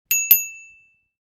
Bike-bell-ding.mp3